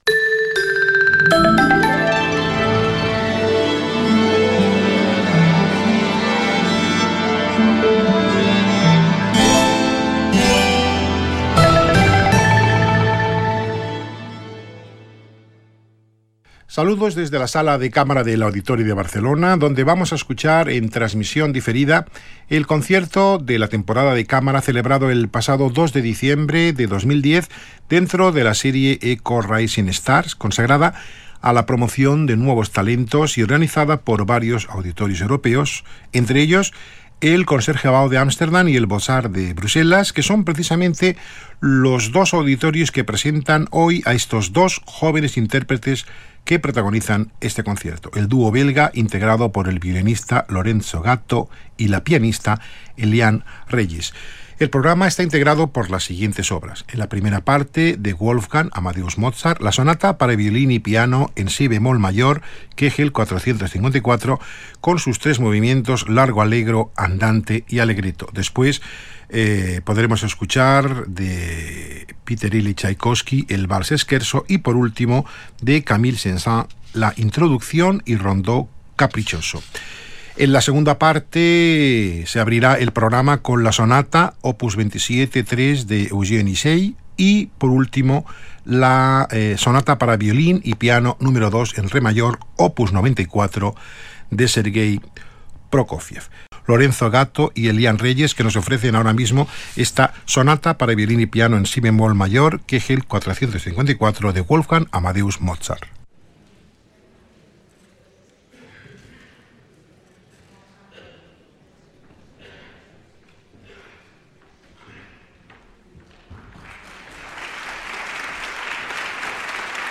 Recital from Barcelona
all recorded live and preserved for posterity by Radio Nacional España on December 2, 2010.